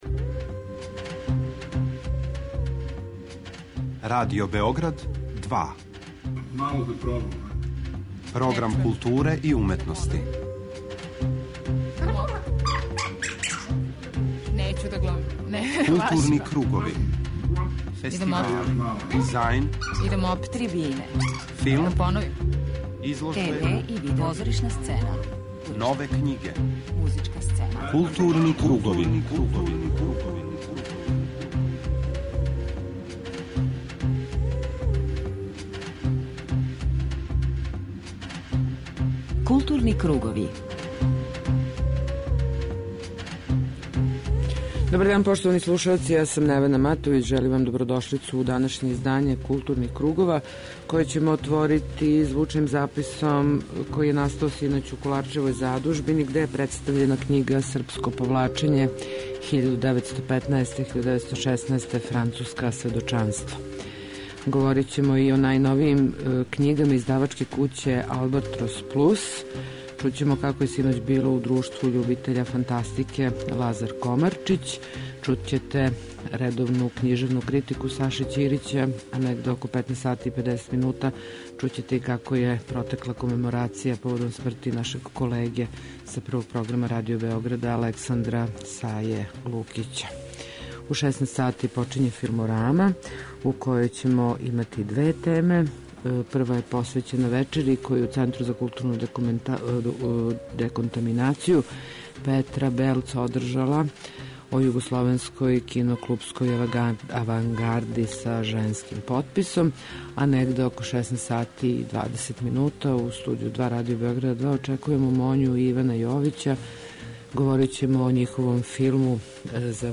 У данашњој емисији чућете звучну забелешку са овог догађаја.